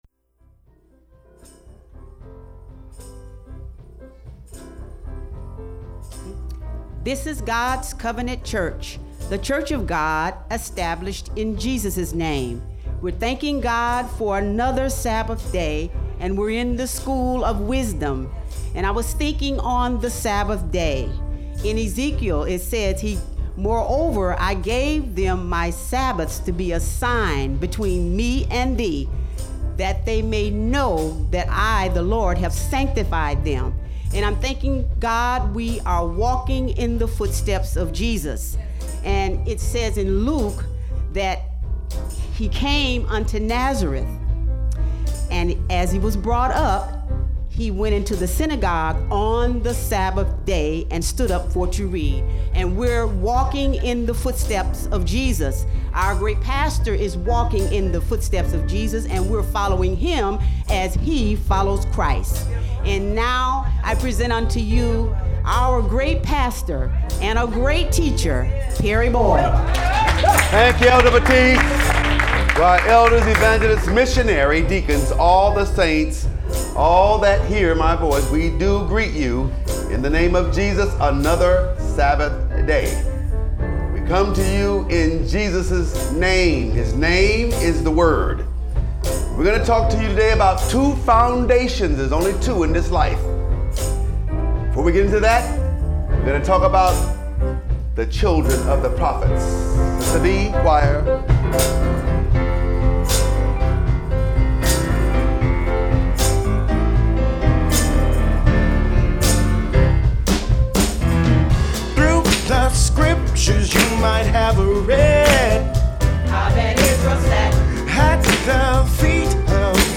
by admin | May 26, 2016 | Audio Stream, Lessons | 0 comments